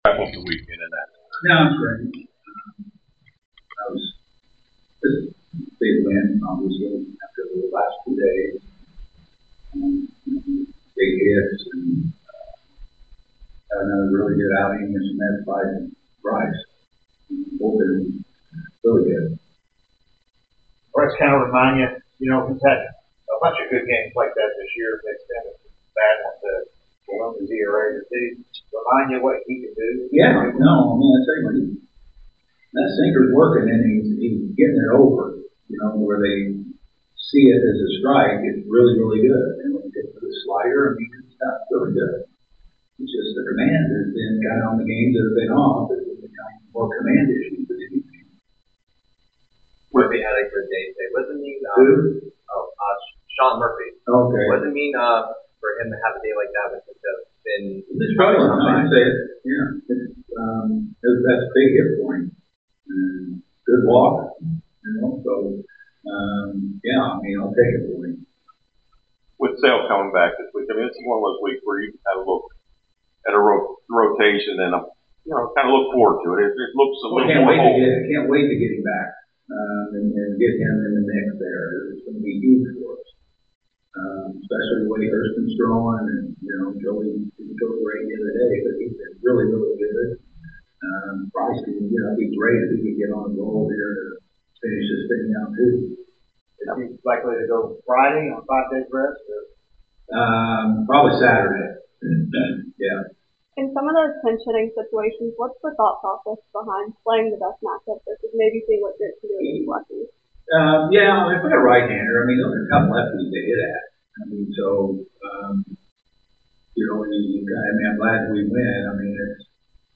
Atlanta Braves Manager Brian Snitker Postgame Interview after defeating the New York Mets at Truist Park.